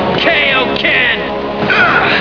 Sound clip of Goku going Kaio-ken